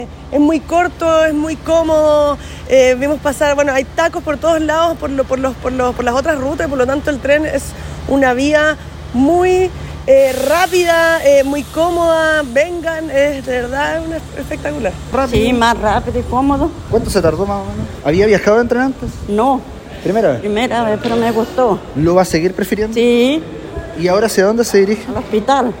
En el segundo tramo salió desde la intermodal La Paloma a Llanquihue, donde sus pasajeros celebraron esta nueva alternativa de transporte público. Los usuarios destacaron la rapidez y lo cómodo del servicio.